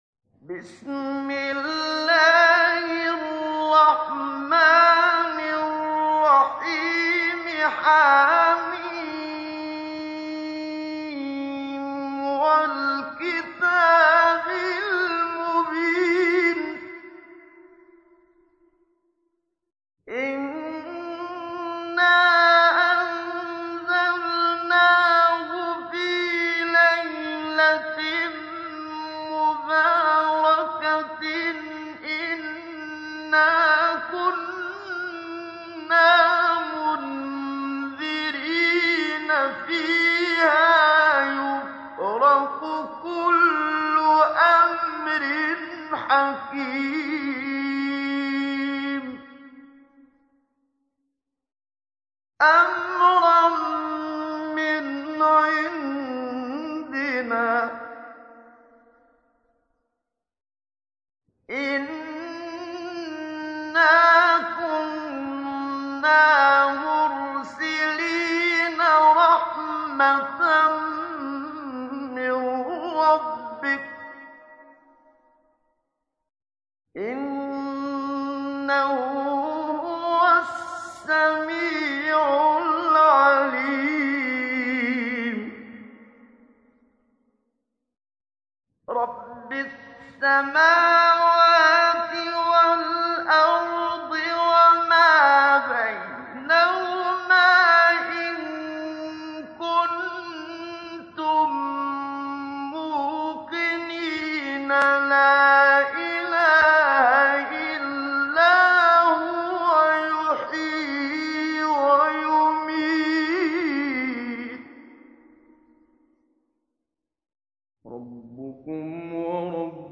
تحميل : 44. سورة الدخان / القارئ محمد صديق المنشاوي / القرآن الكريم / موقع يا حسين